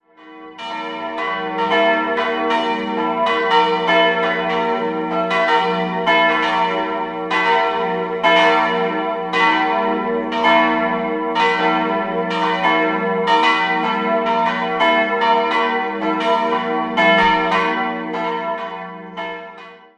Jahrhundert. 3-stimmiges Geläute: e'-fis'-ais' Die kleine Glocke stammt aus der Gießerei Hamm in Regensburg, die beiden großen (offizielle Schlagtöne: e'+2 und g'-8) wurden 1950 von Wendelin Vielwerth in Ingolstadt gegossen.